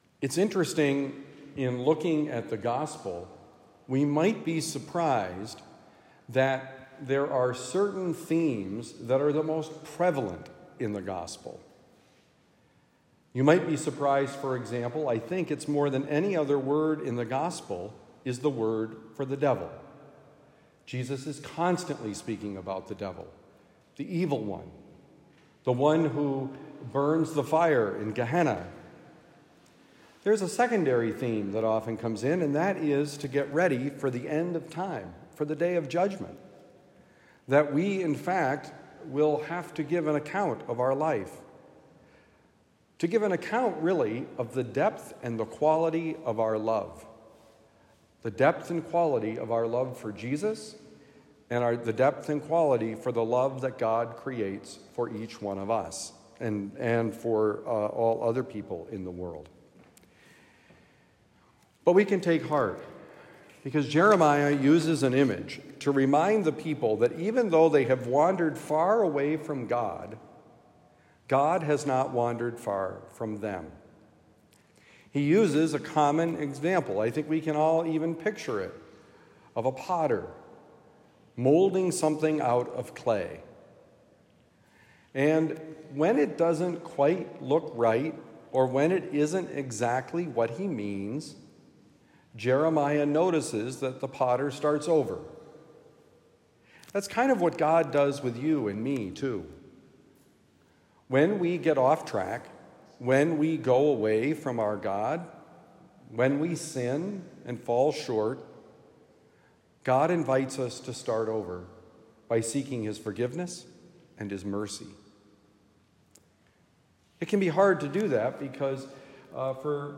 Jesus mentions the devil a lot: Homily for Thursday, August 1, 2024